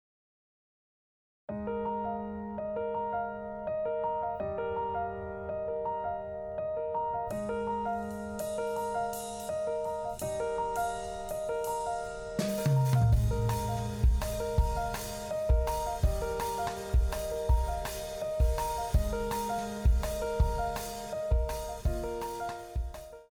今回は2:1に設定をしているので、元の音量の二分の一になっているのがわかります。
・コンプありの音声
元の音より小さくなっていますね！
しかし、この音声には少しツンツンした感じと、音量上下のフワフワ感があります。